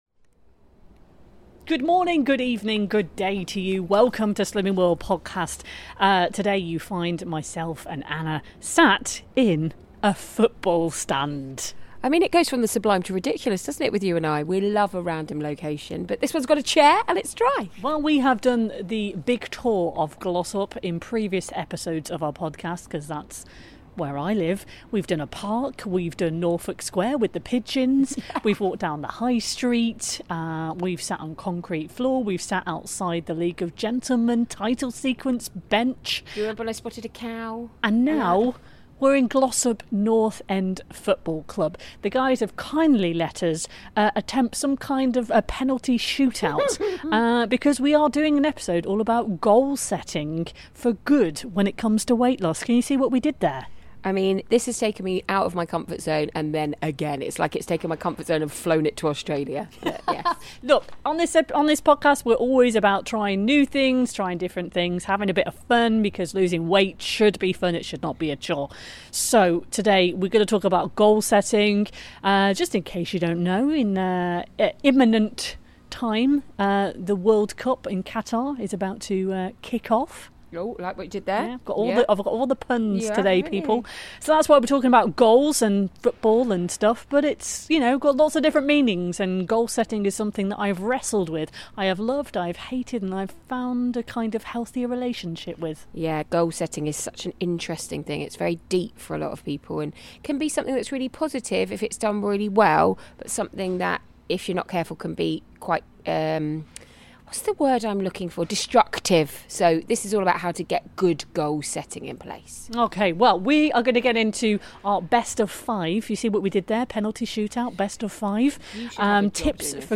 Plus, with the Qatar World Cup 2022 about to kick off, they head to a local football club to have a go at a penalty shoot out.